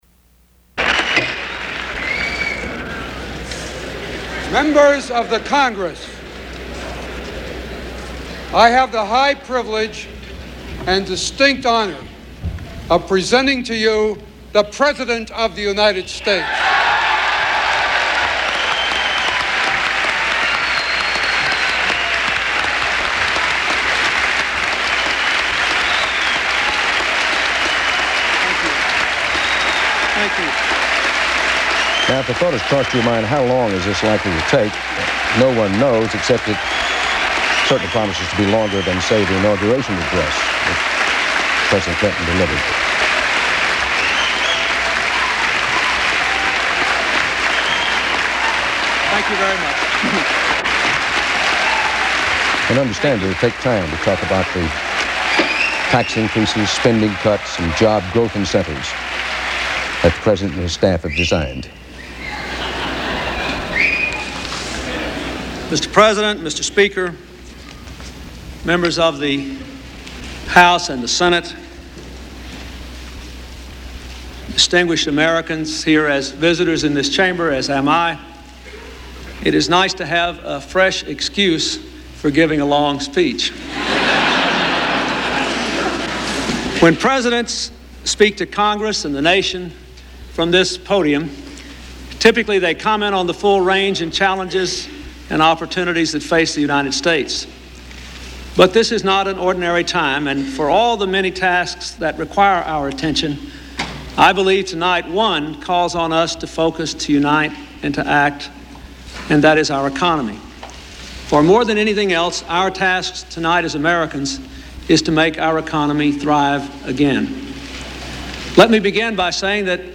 President Bill Clinton delivers his first State of the Union address